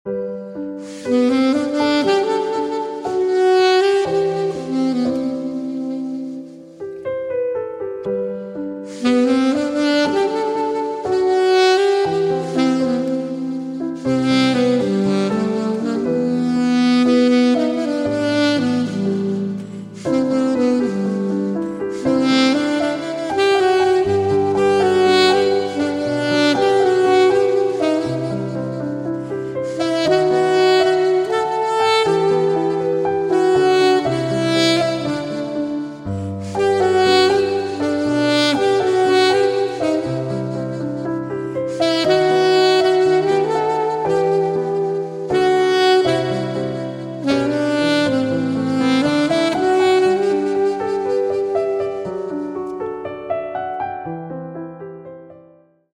Romantic